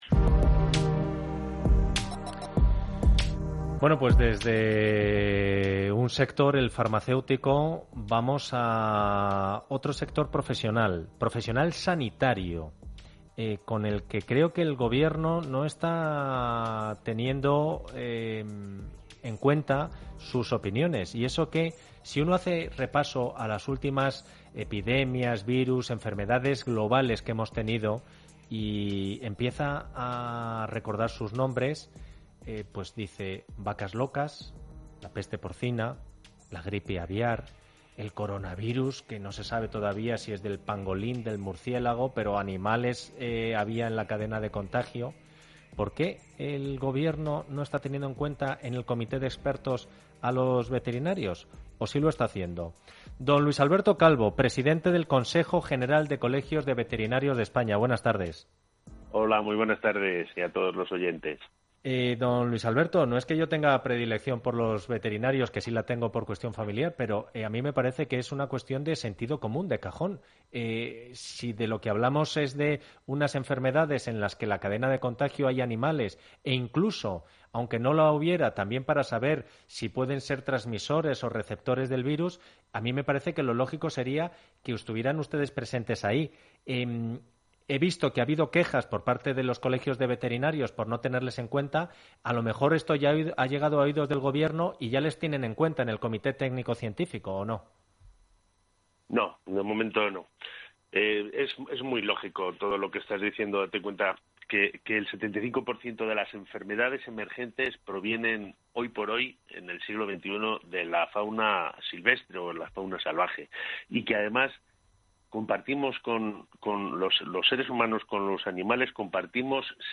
Aquí puede descargarla: Entrevista